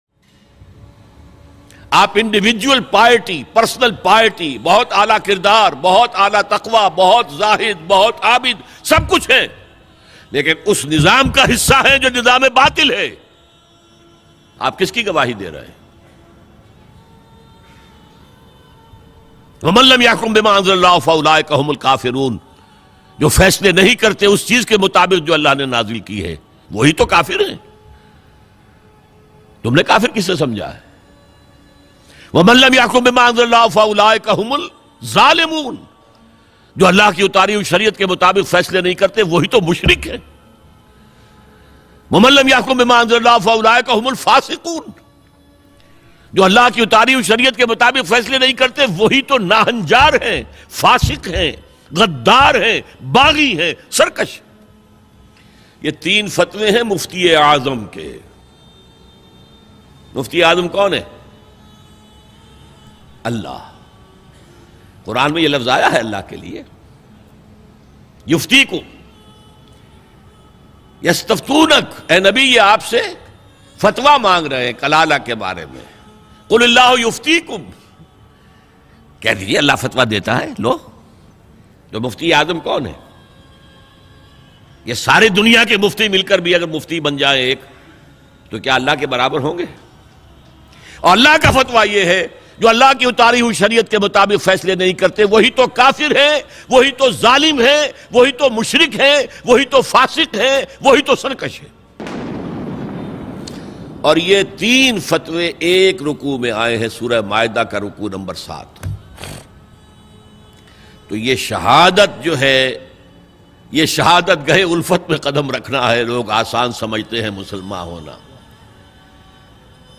Mufti Azam Ke 3 Fatway Dr Israr Ahmed Bayan MP3 Download